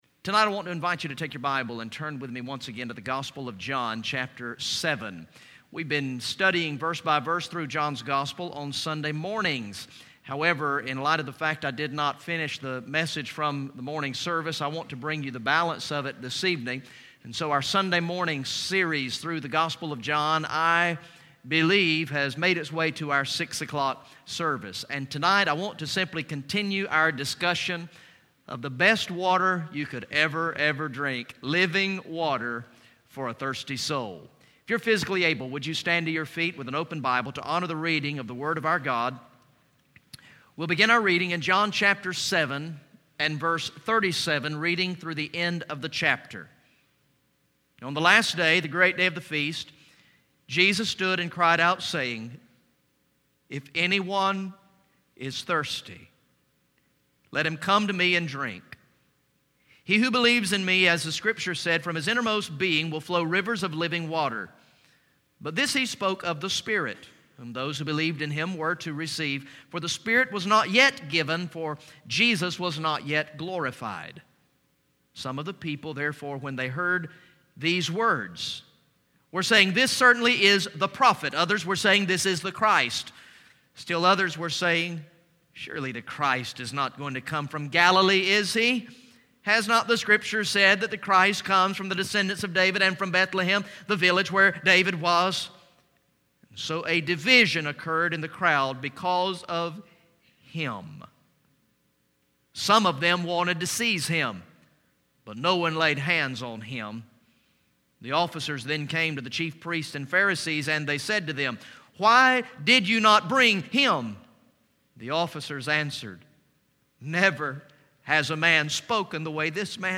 Message #26 from the sermon series through the gospel of John entitled "I Believe" Recorded in the evening worship service on Sunday, November 9, 2014